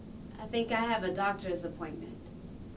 HAS-Corpus / Audio_Dataset /sad_emotion /1024_SAD.wav
1024_SAD.wav